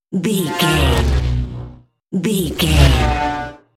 Sci fi vehicle pass by fast
Sound Effects
futuristic
pass by
vehicle